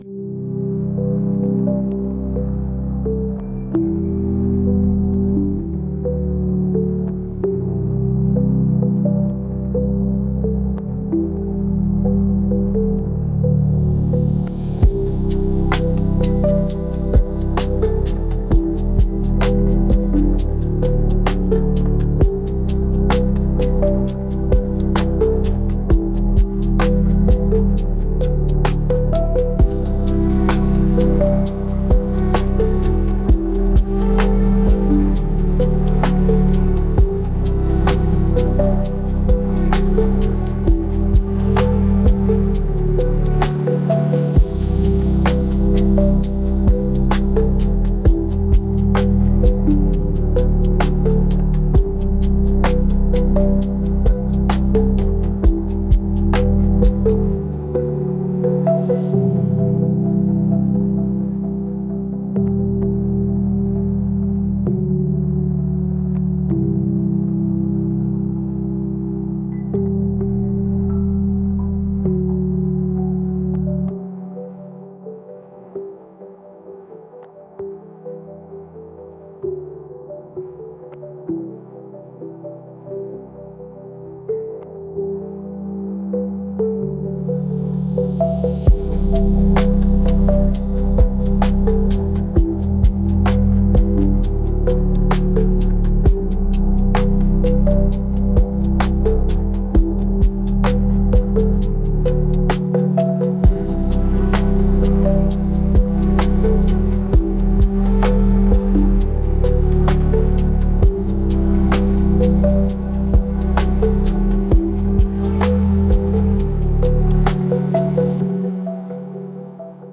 ヒーリング効果のあるの環境音のような曲です。